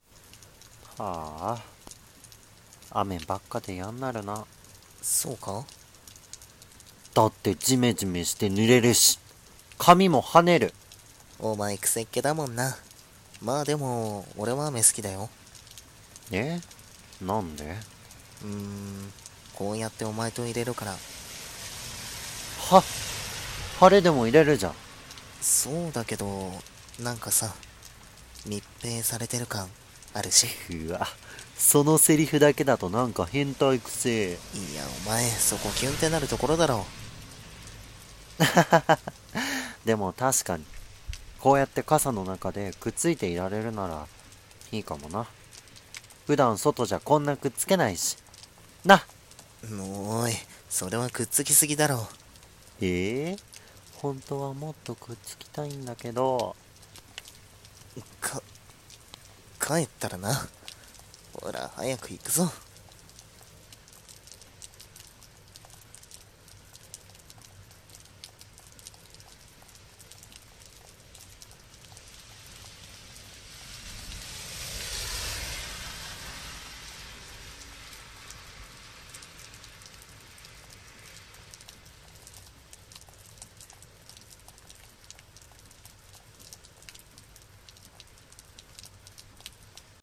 雨の日も【声劇